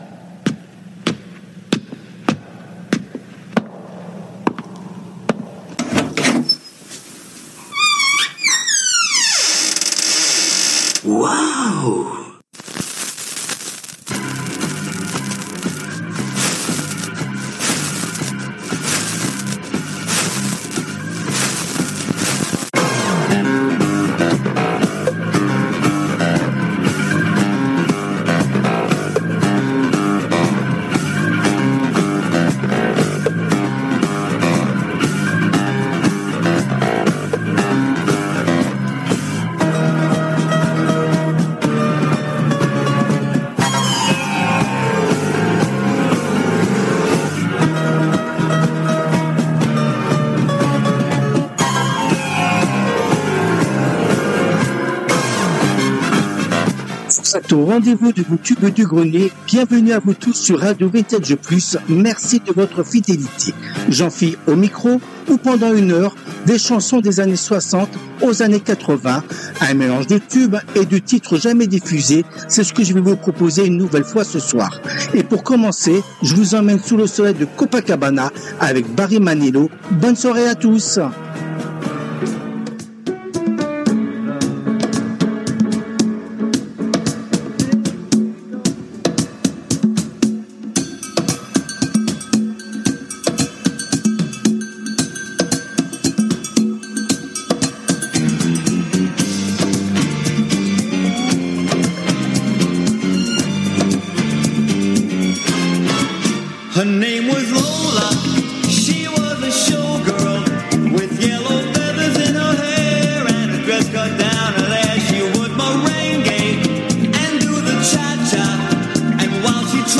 Les Tubes du Grenier Les Tubes connus ou oubliés des 60's, 70's et 80's